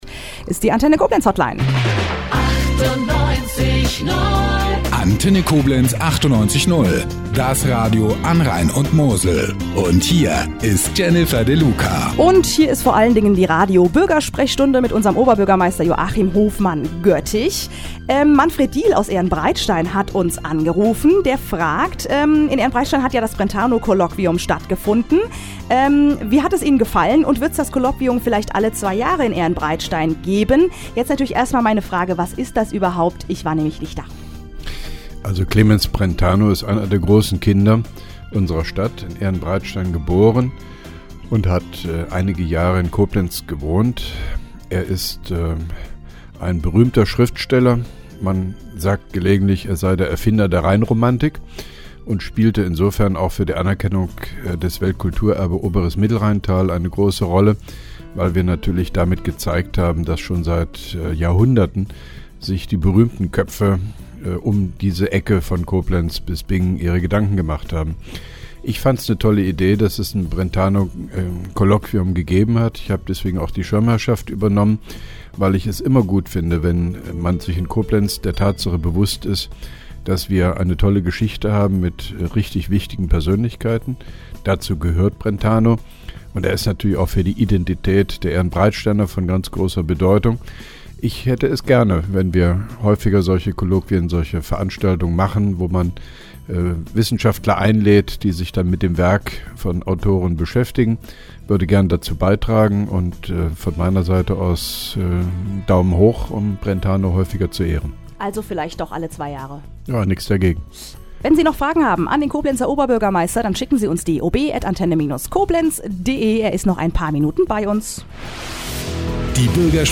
(2) Koblenzer OB Radio-Bürgersprechstunde 14.09.2010